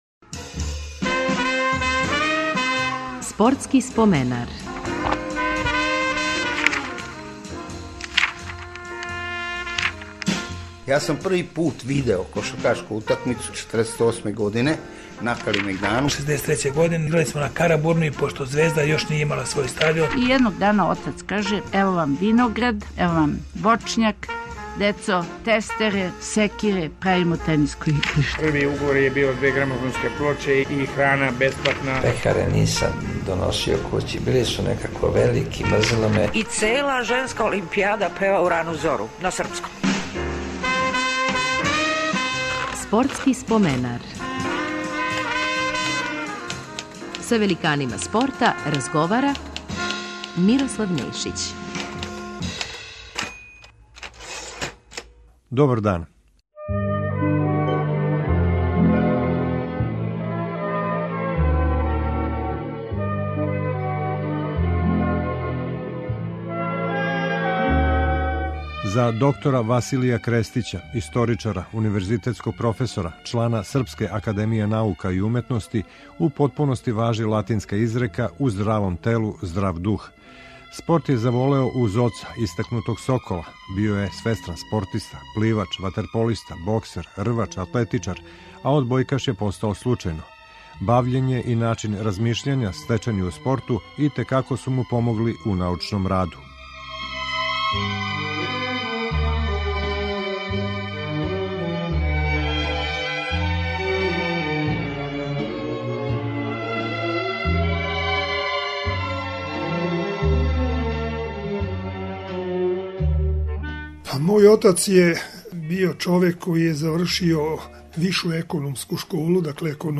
Репризираћемо емисију у којој је гост одбојкаш академик доктор Василије Крестић.